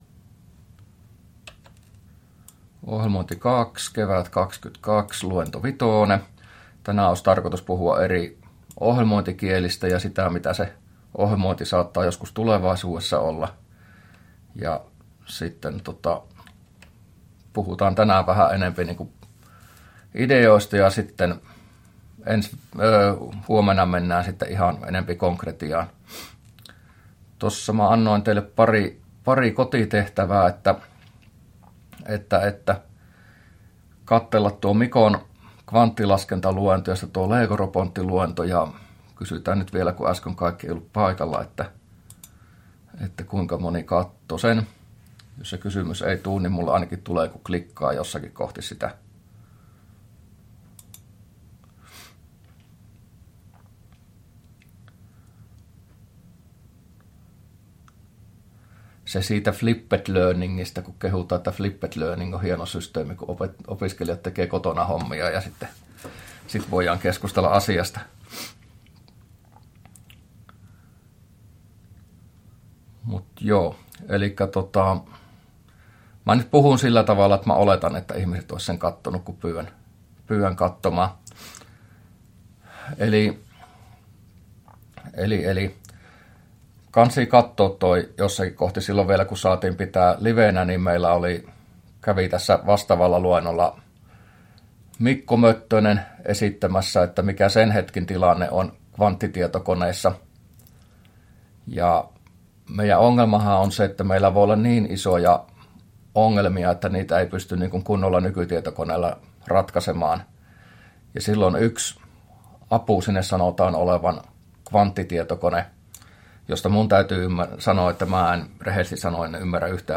luento05a